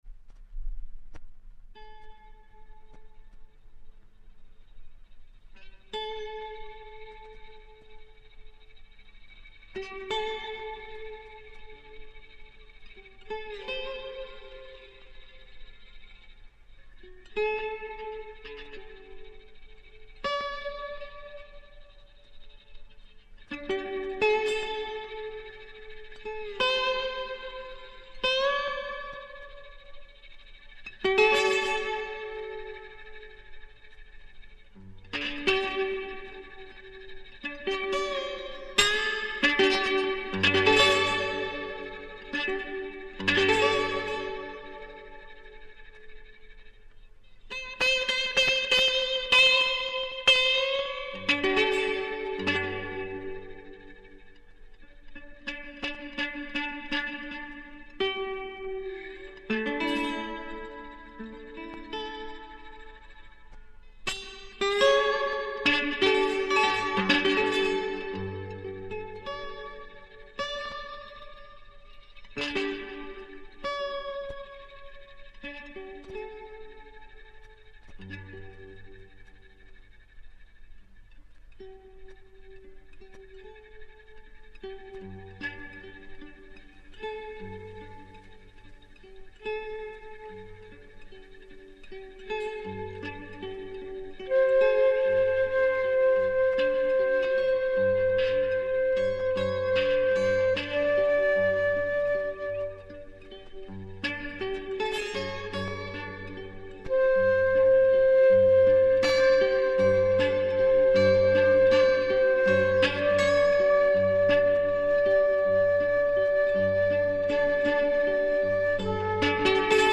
gtr
fluit